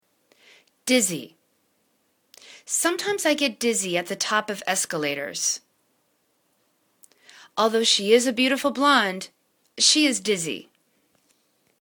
diz.zy     /'dizi/    adj